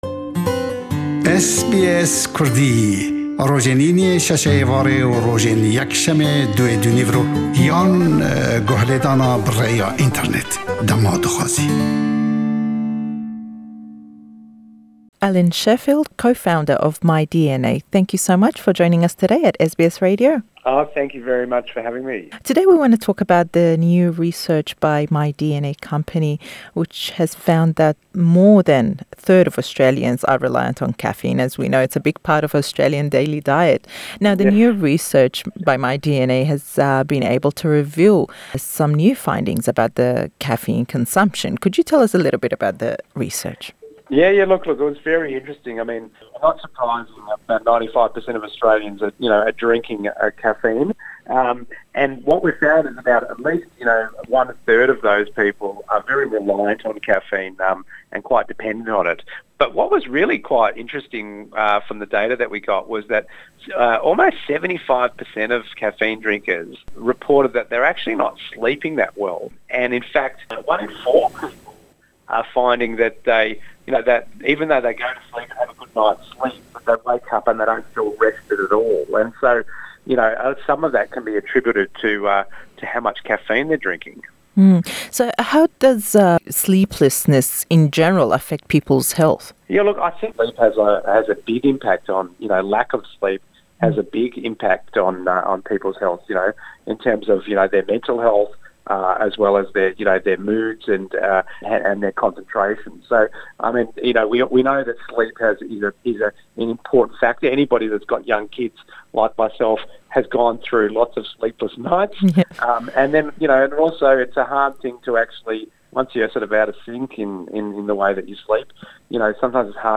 lêdwanêke